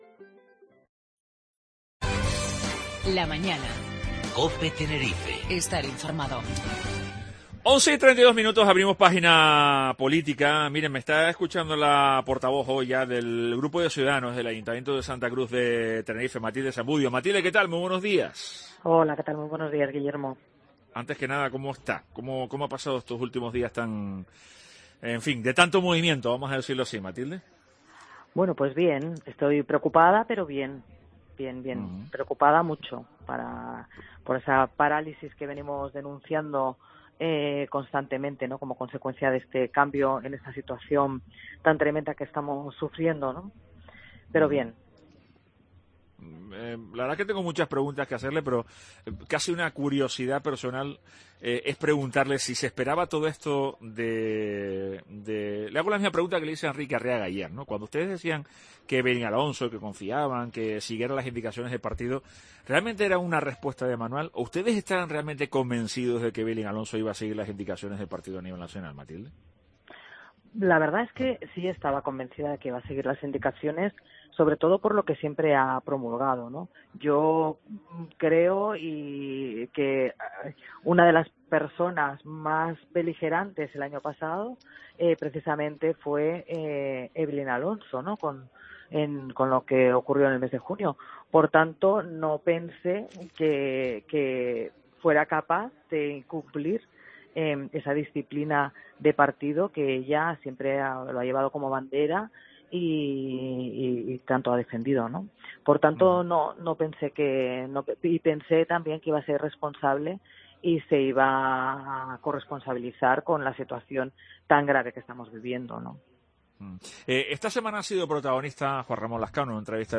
La portavoz del grupo de Ciudadanos en el Ayuntamiento de Santa Cruz de Tenerife Matilde Zambudio, afirmó hoy en La Mañana de COPE Tenerife, que “es rotundamente falso” que durante el último año de gobierno municipal se trabajara en base a “intereses particulares”.